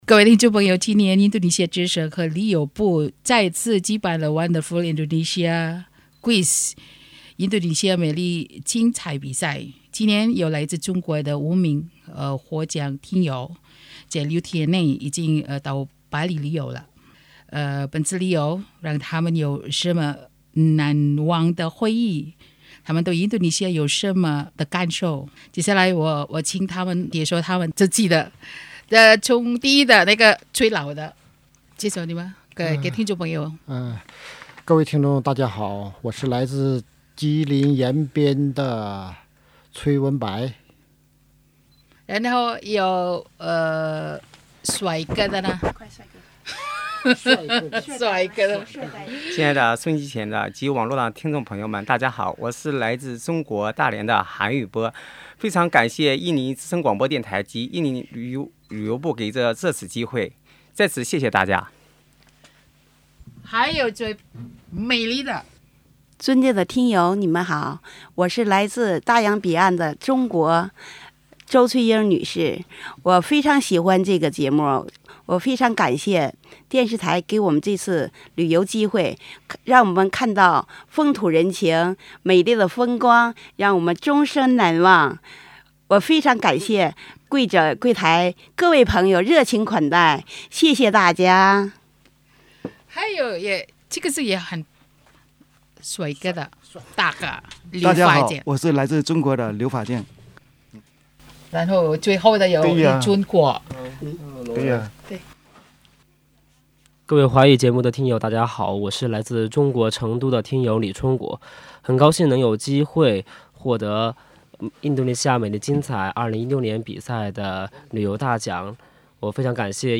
wawancara pemenang kuis edited.mp3